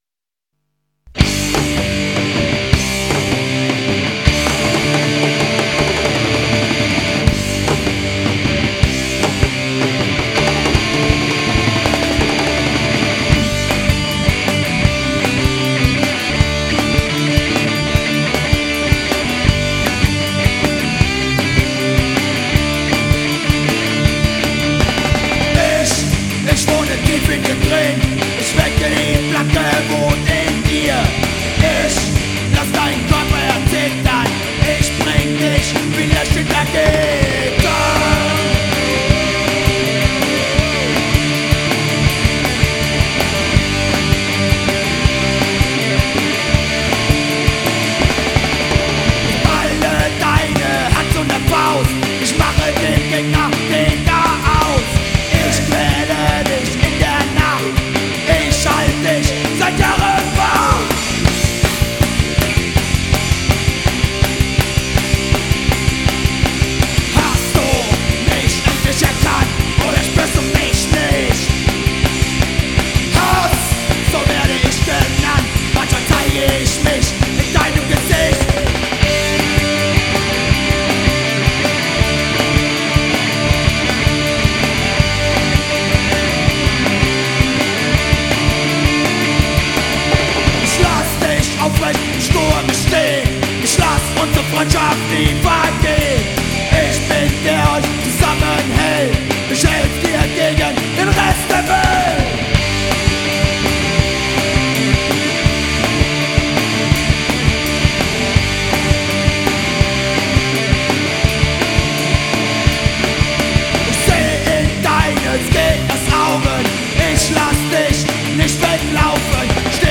Ehrlicher Oi! von und für die Straße